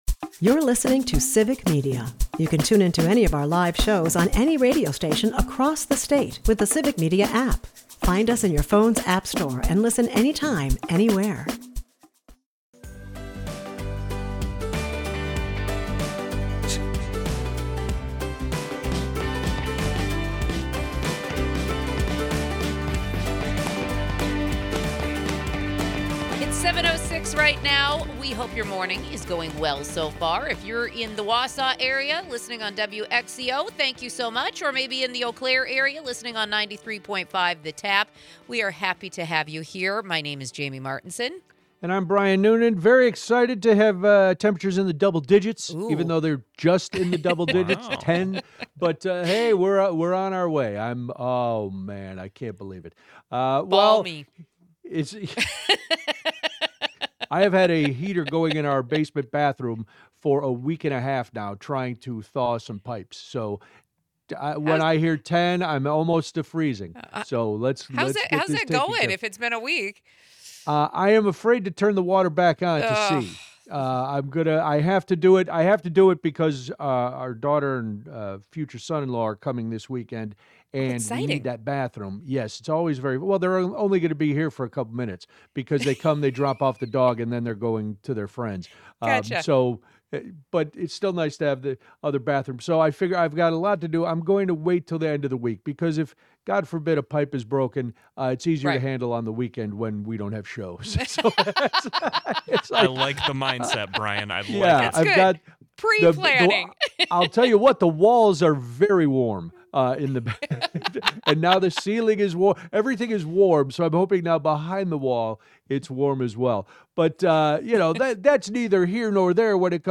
Wisconsin’s 4th District Representative Gwen Moore joins the program to discuss her push for the state Supreme Court to take up the Bothfeld v. Wisconsin Election Commission case to challenge what she describes as "rigged" congressional maps that disproportionately favor Republicans. The hour ends by introducing Anson and Avro, two local rescues from Underdog Pet Rescue of Wisconsin who are set to compete in the 2026 Puppy Bowl, with a meet-and-greet event scheduled in Madison this Sunday.